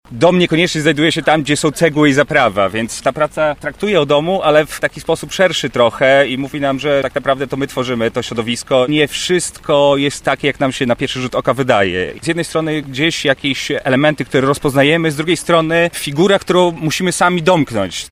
Podczas wczorajszego spaceru twórcy opisywali słuchaczom swoje dzieła.
Mimo niesprzyjającej pogody mieszkańcy z zainteresowaniem słuchali wypowiedzi artystów.